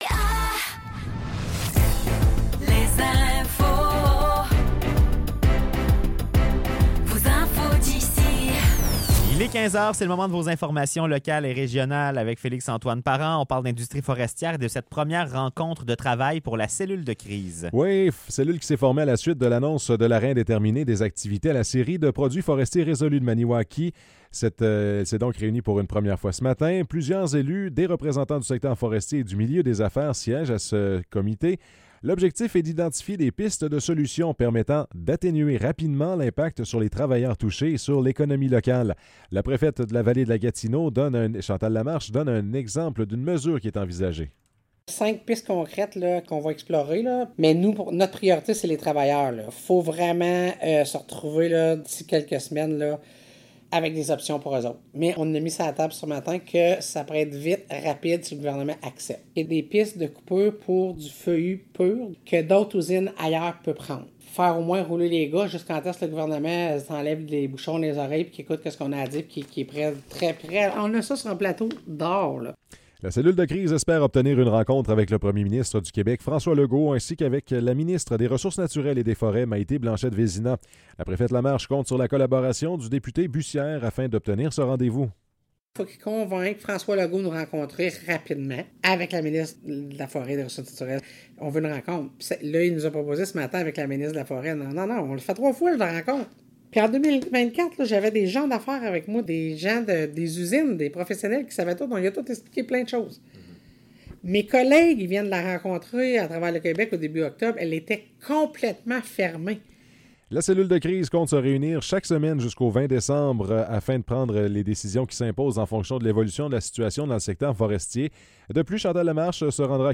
Nouvelles locales - 18 octobre 2024 - 15 h